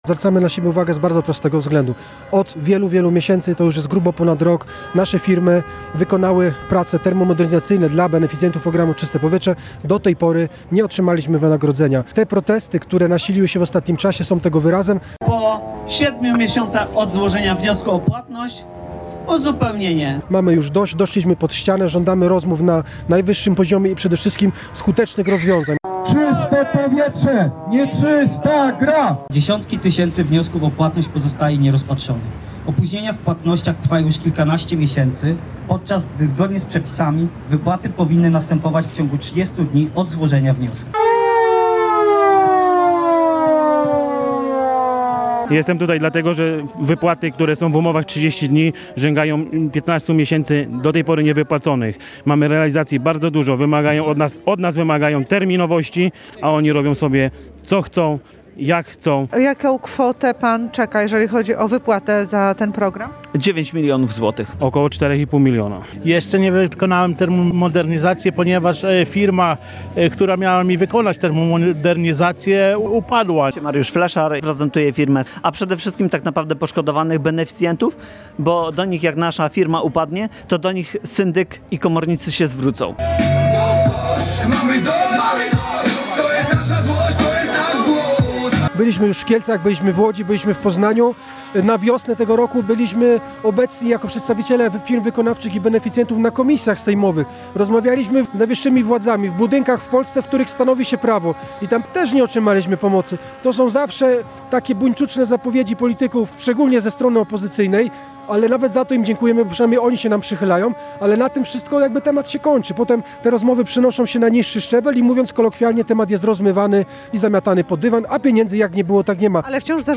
Przed siedzibą Funduszu przy ul. Zygmuntowskiej zgromadziło się kilkudziesięciu przedsiębiorców i beneficjentów programu. Głośno wyrażali swoją dezaprobatę z powodu opóźnień w płatnościach i domagają się uproszczenia formalności.
Protestujący, którzy wypowiadali się na naszej antenie, mówili nawet o kilkumilionowych zaległych należnościach za wykonane usługi, takie jak docieplanie domów czy wymiana źródeł ciepła na proekologiczne.